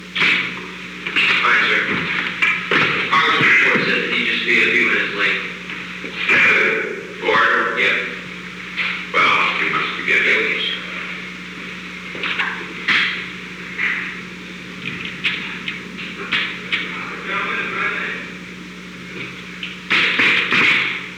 Secret White House Tapes
Conversation No. 908-7
Location: Oval Office
The President met with an unknown man.